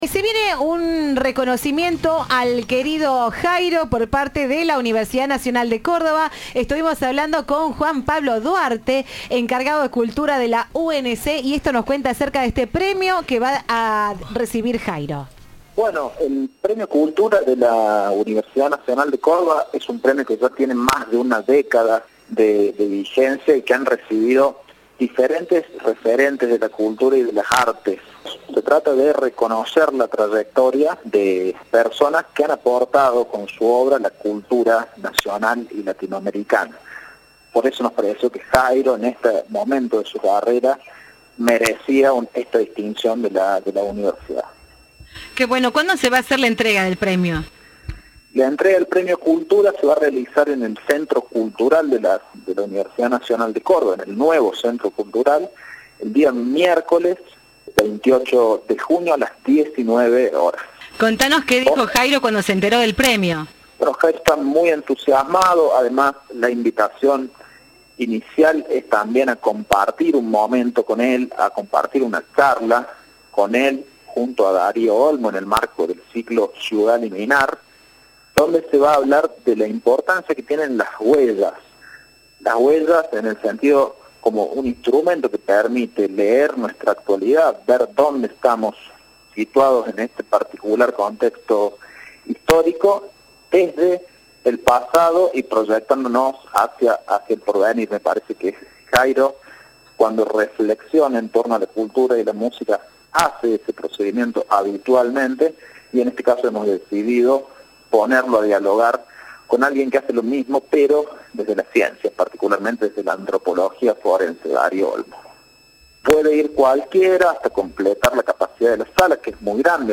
“Que a uno lo distinga una entidad así, es algo extraordinario”, dijo el artista a Cadena 3.